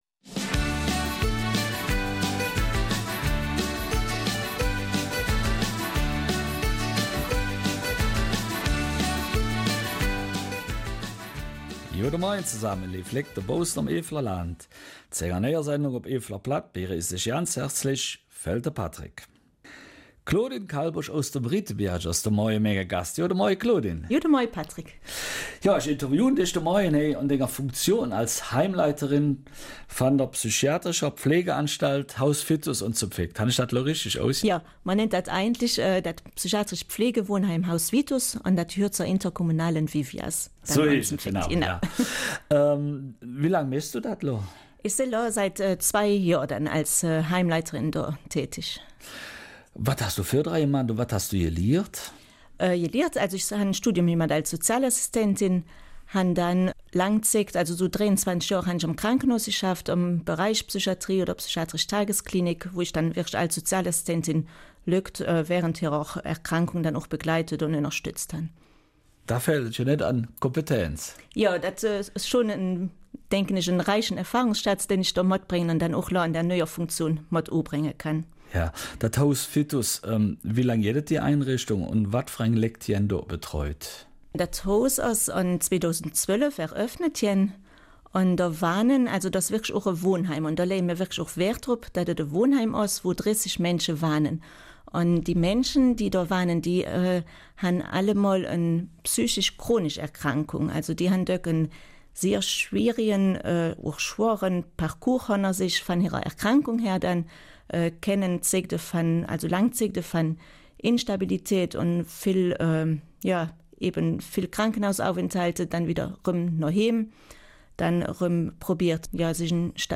Eifeler Mundart - 8. Juni